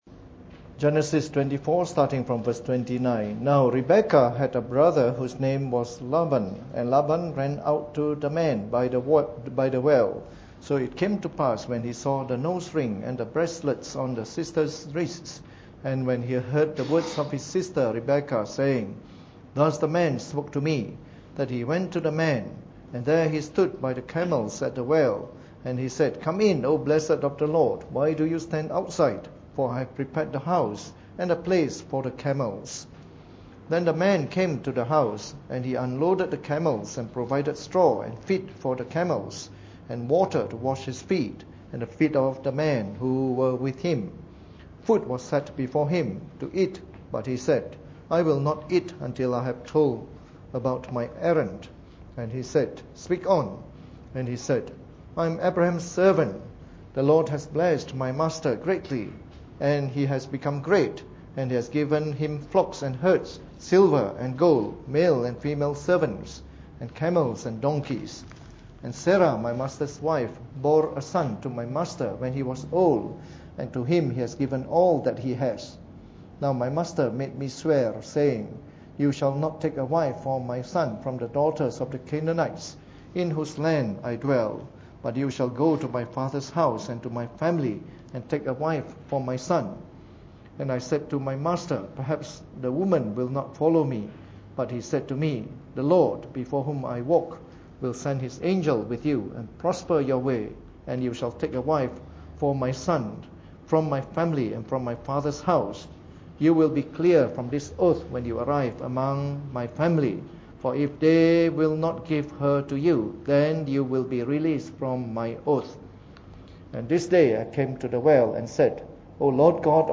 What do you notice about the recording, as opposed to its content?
Preached on the 15th of July 2015 during the Bible Study, from our series on “Christian Marriage.”